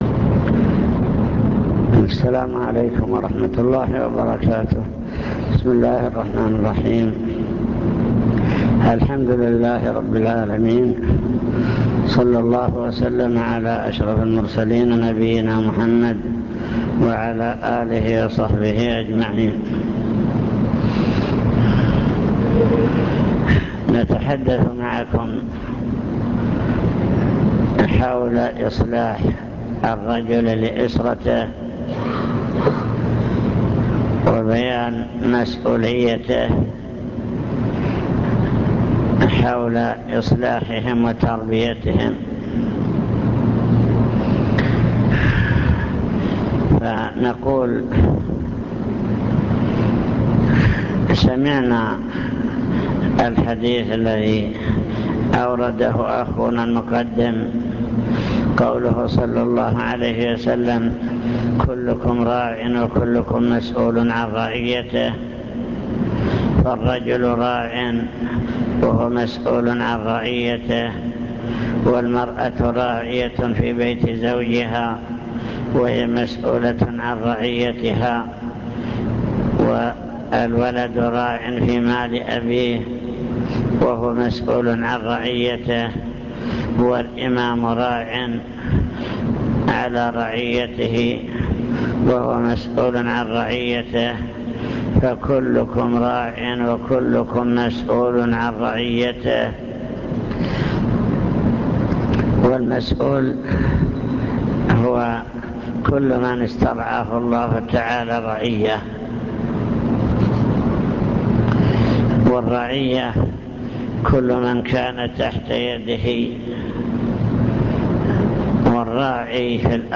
المكتبة الصوتية  تسجيلات - محاضرات ودروس  محاضرة عن تربية الأسرة واجب الأب نحو أسرته